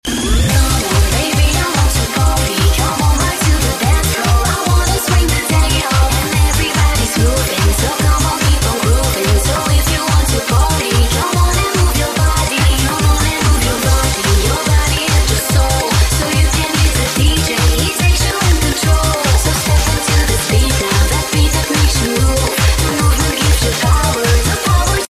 Dance & Trance